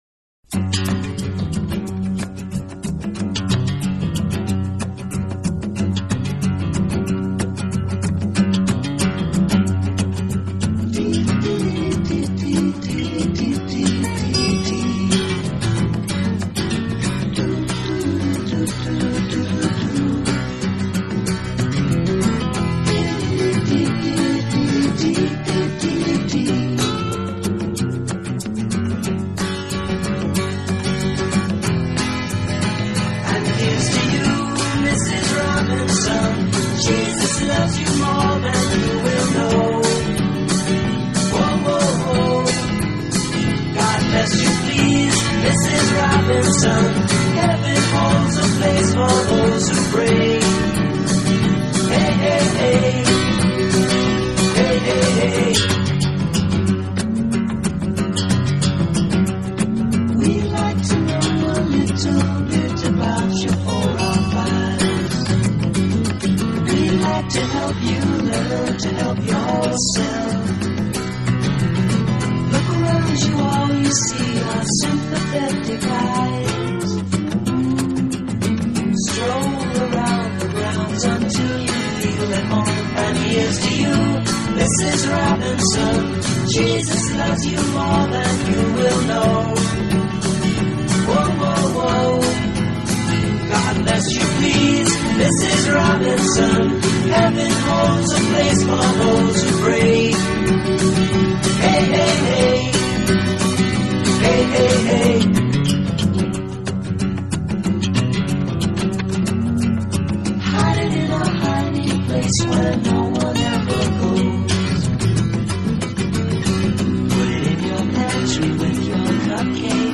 Folk Rock, Pop